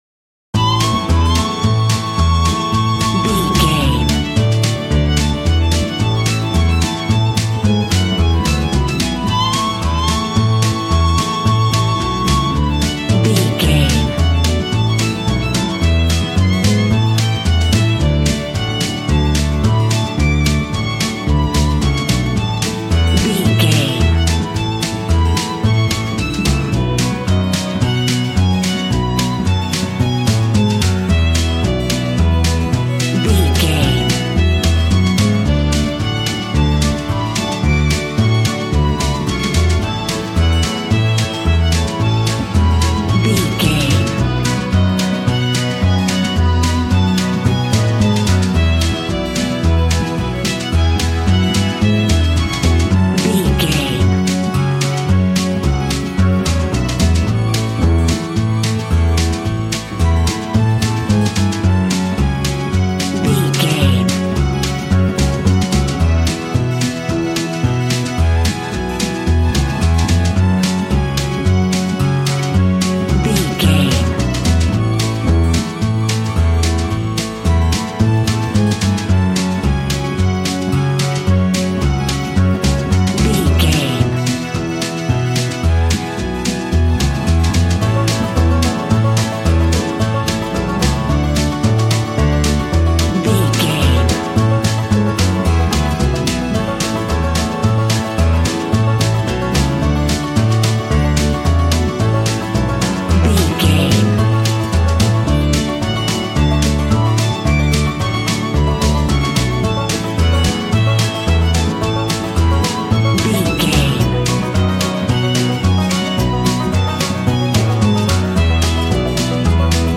Country music and fiddles go hand in hand!
Ionian/Major
Fast
fun
bouncy
double bass
drums
acoustic guitar